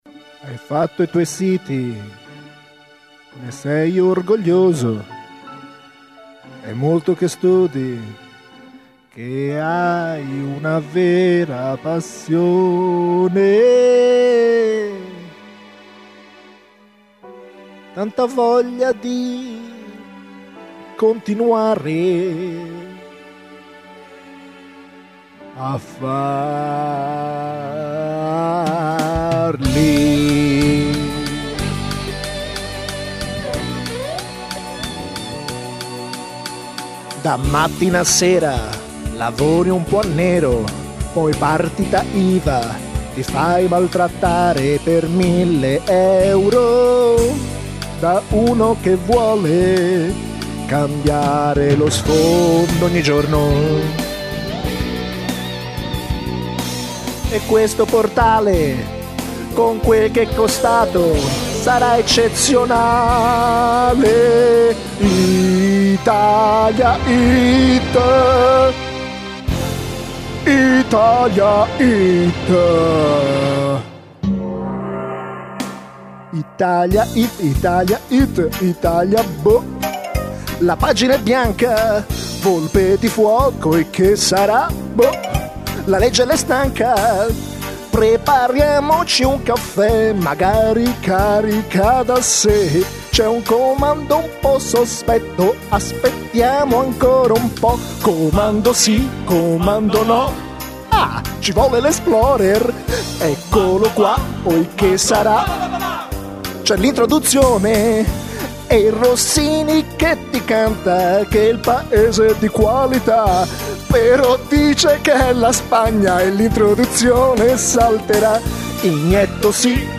Un po' stonatello...